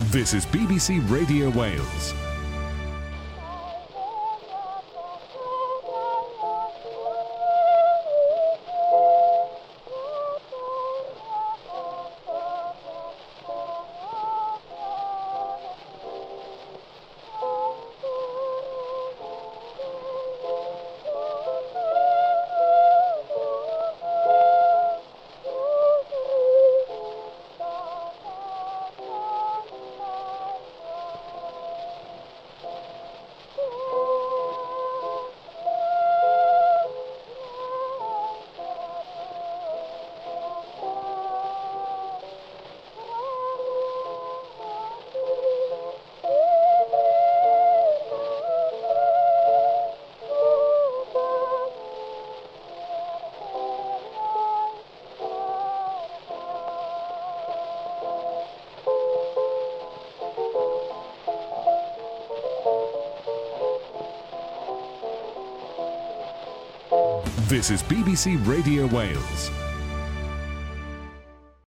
Must Listen: First ever recording of the Welsh National Anthem (made in 1899)